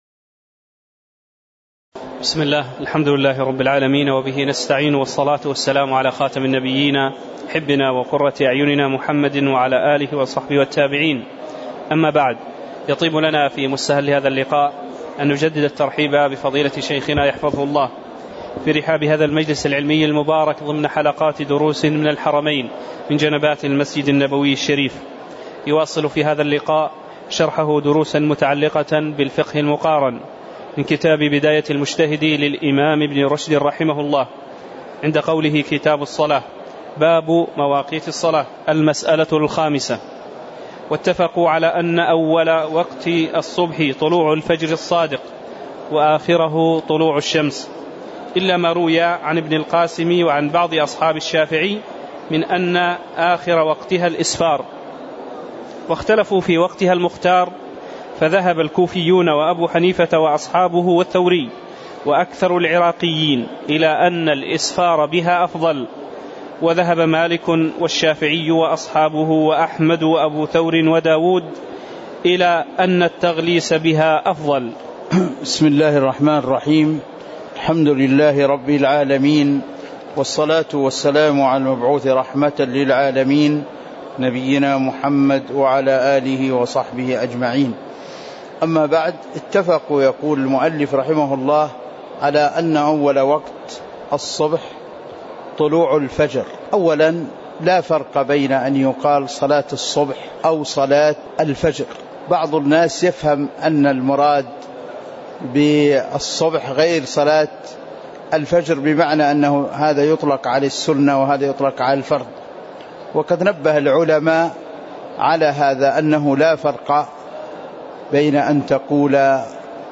تاريخ النشر ٨ صفر ١٤٤١ هـ المكان: المسجد النبوي الشيخ